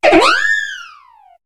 Cri de Farfaduvet dans Pokémon HOME.